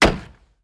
Chopping wood 7.wav